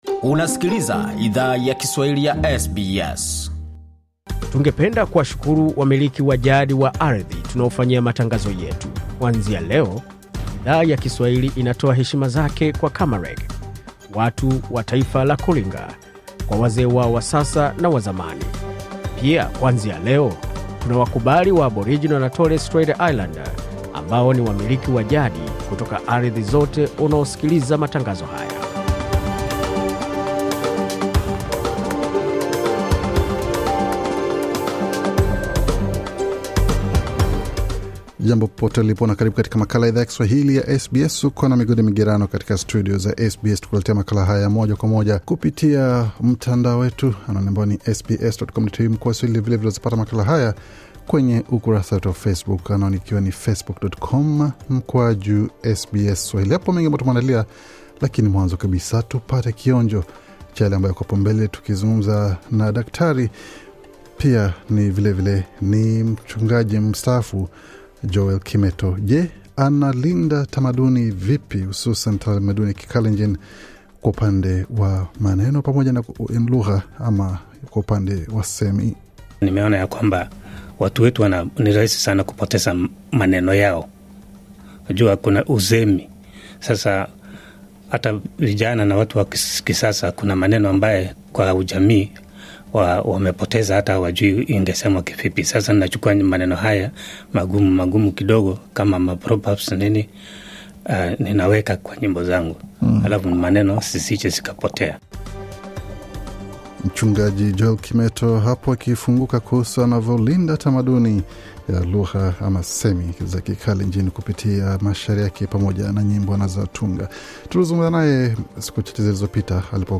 Bango la taarifa ya habari la SBS Kiswahili